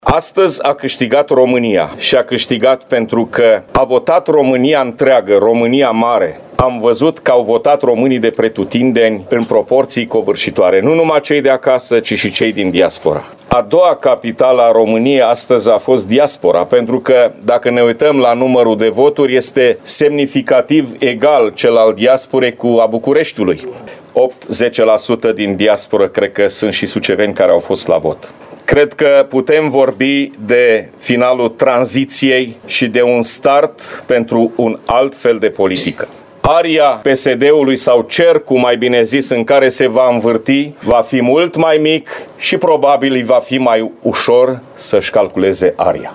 La sediul PNL, într-o atmosferă de bucurie, președintele GHEORGHE FLUTUR a declarat că “victoria lui IOHANNIS este o victorie pentru România” și a remarcat contribuția diasporei la acest rezultat.